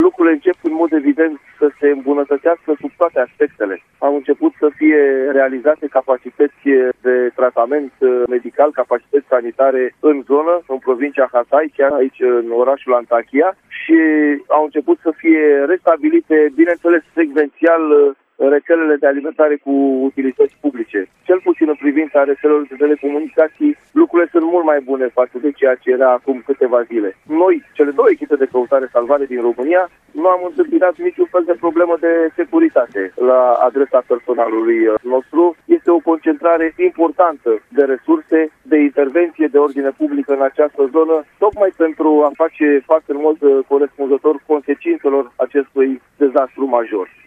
după cum a declarat la Apel Matinal, la RRA